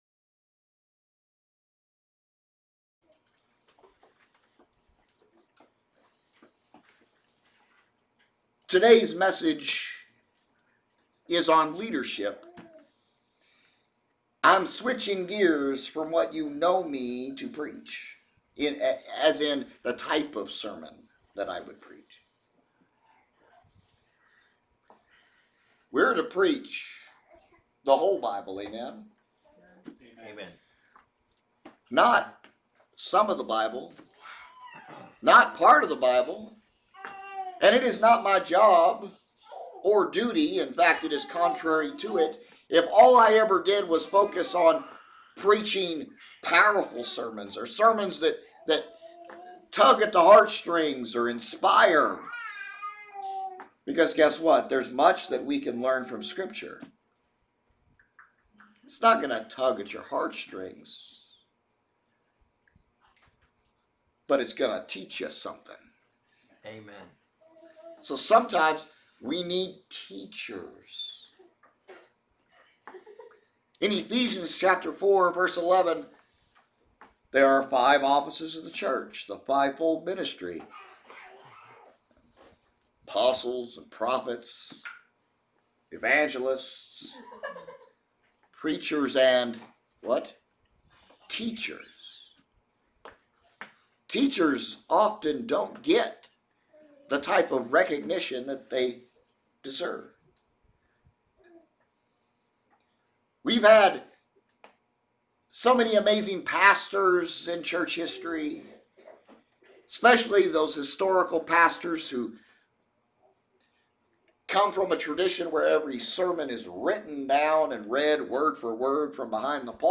Passage: Proverbs 16 Service Type: Sunday Service